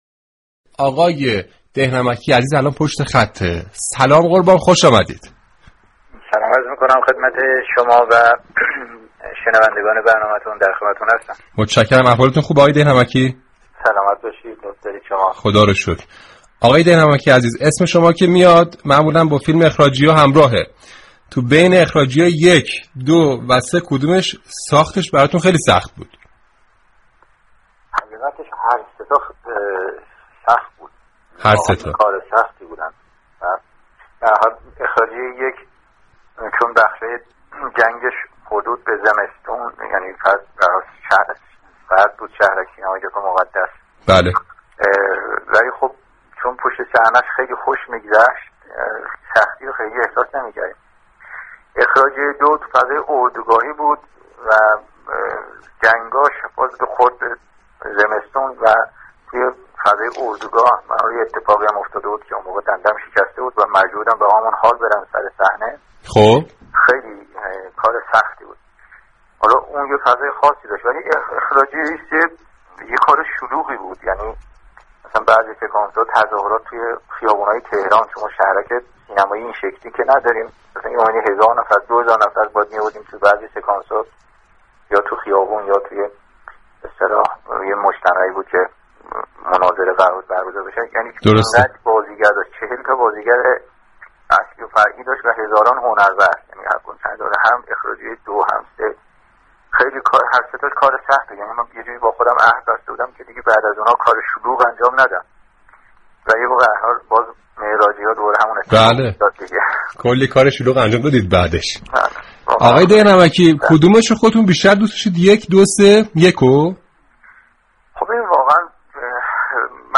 رادیو صبا در برنامه «همپای صبا» با مسعود ده نمكی درباره فیلم های سینمایی اخراجی گفتگو صمیمی داشت.